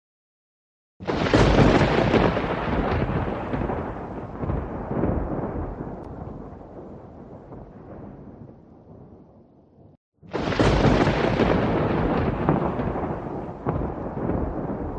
Thunderstorm
Thunderstorm is a free ambient sound effect available for download in MP3 format.
075_thunderstorm.mp3